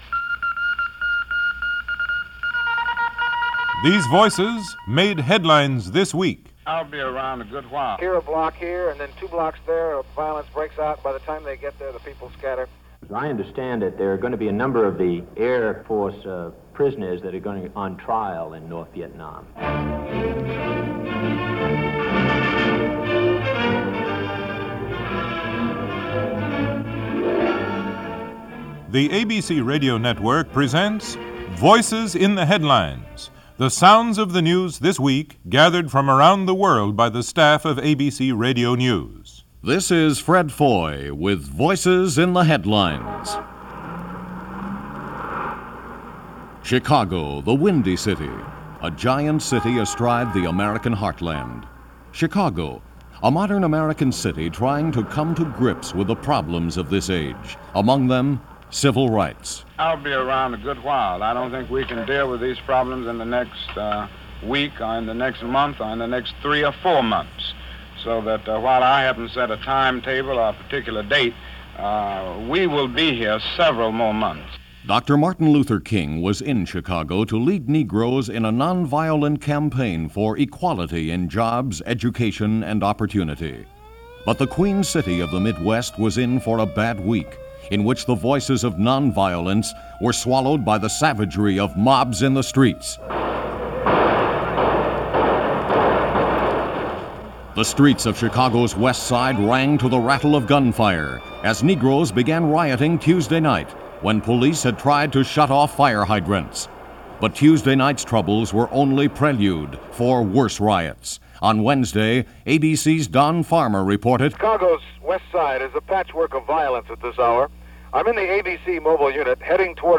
ABC Radio Voices In The Headlines